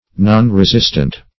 Nonresistant \Non`re*sist"ant\, a.